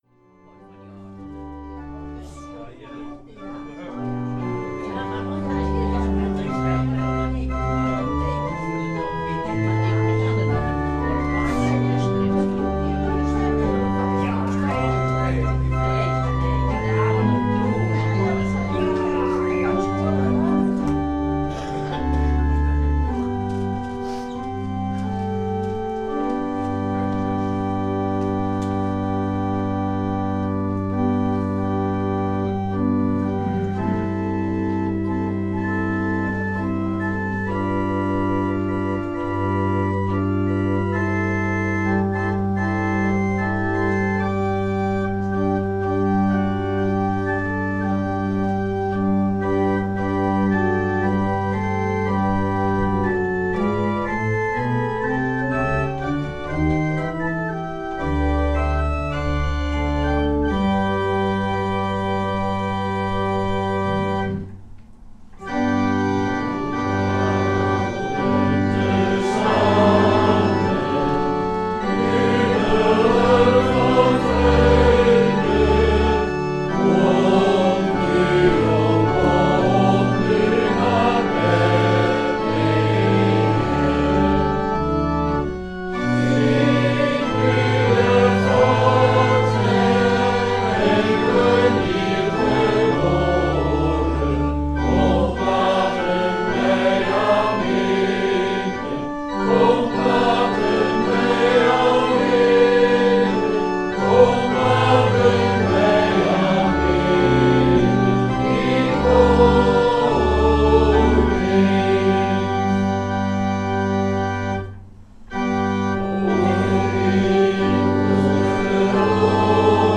Meditatie 1 : Jezus geboren gelijk aan ons Meditatie 2 :Jezus geboren tot redding van ons Meditatie 3 : Jezus geboren als opdracht voor ons